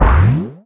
CARTOON_Boing_mono.wav